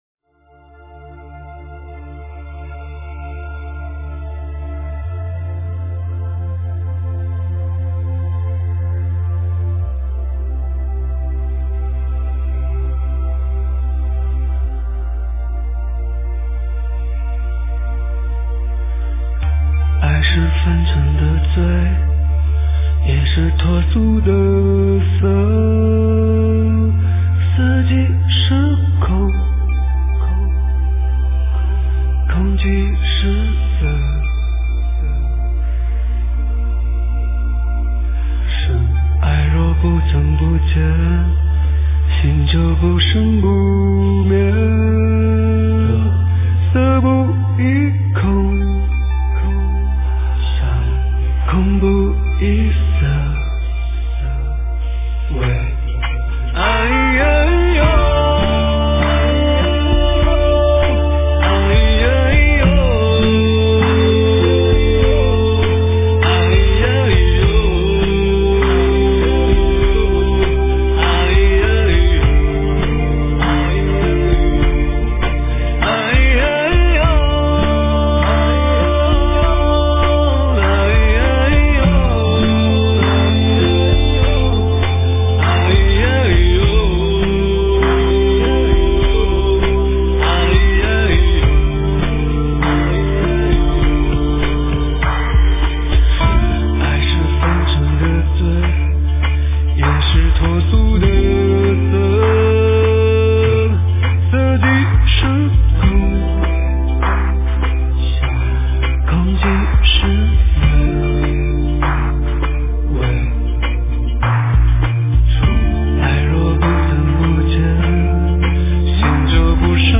佛音 诵经 佛教音乐 返回列表 上一篇： 大悲咒 下一篇： 大悲咒 相关文章 心里的光明 心里的光明--佛教音乐...